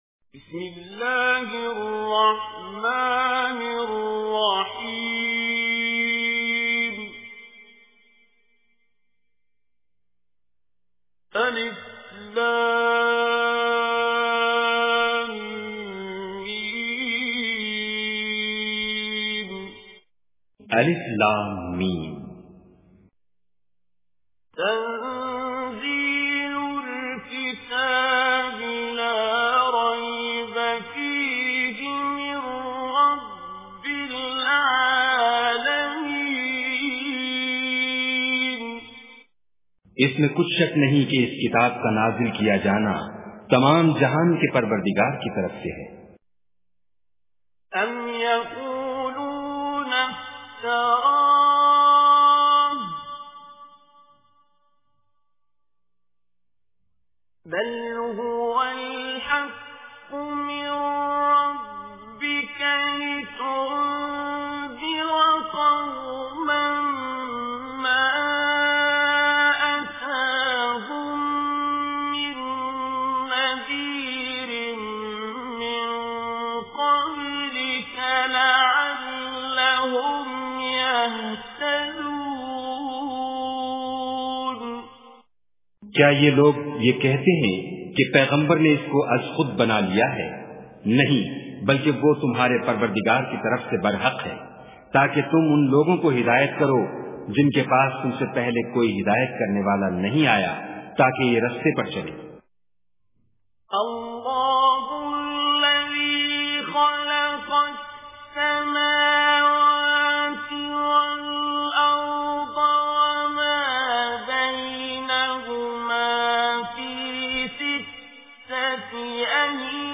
Surah Sajdah Recitation with Urdu Translation
Listen online and download beautiful recitation / tilawat of Surah As Sajdah in the voice of Qari Abdul Basit As Samad.